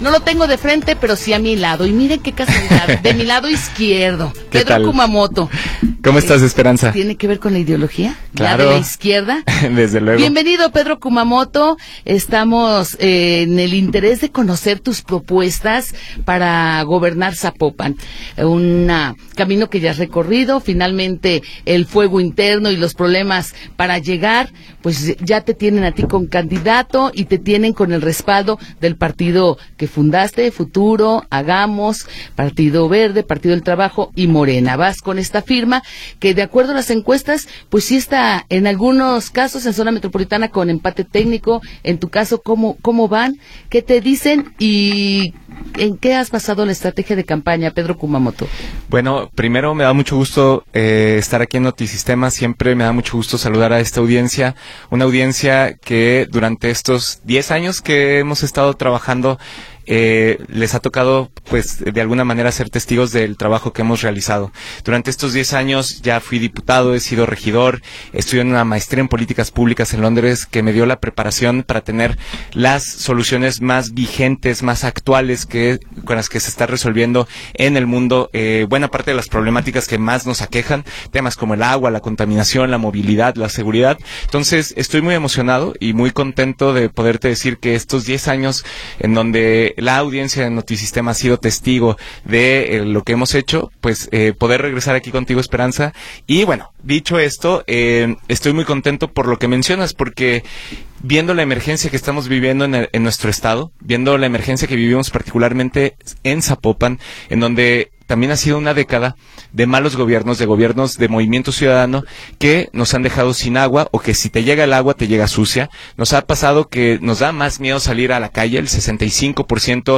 Entrevista con Pedro Kumamoto